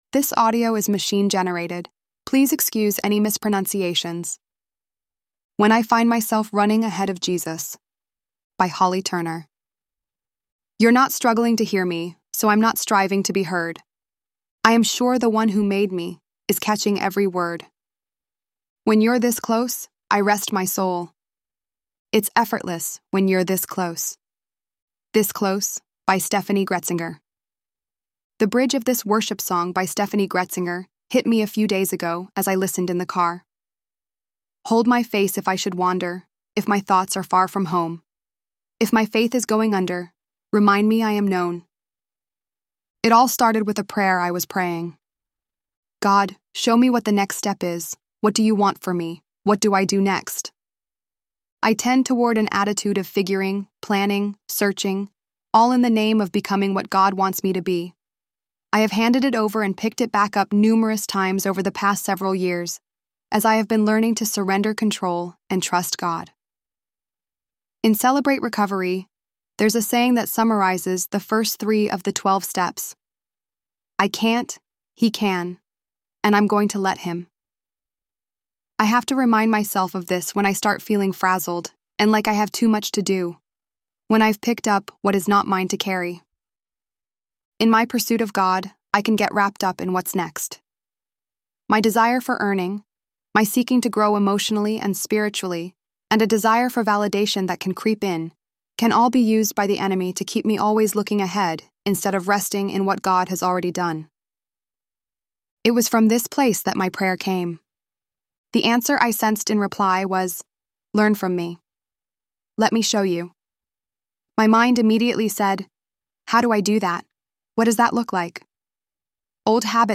ElevenLabs_6.11_Running_Ahead.mp3